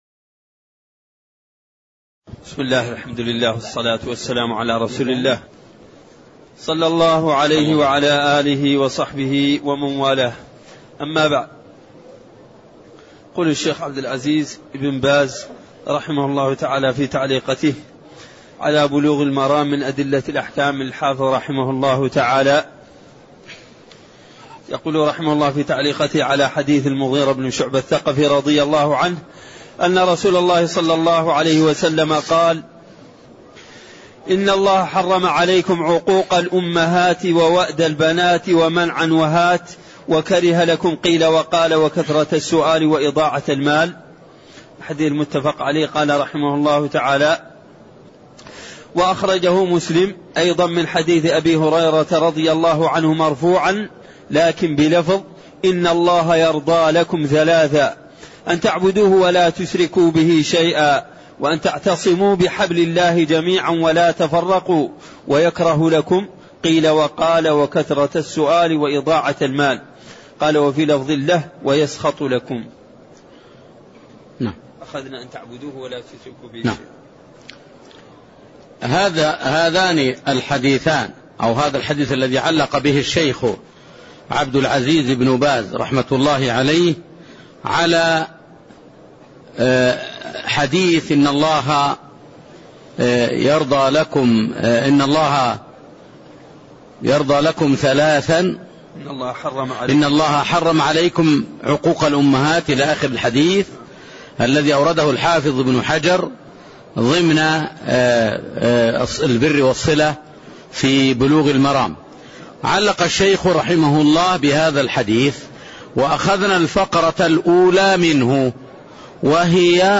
تاريخ النشر ٢٢ رجب ١٤٣١ هـ المكان: المسجد النبوي الشيخ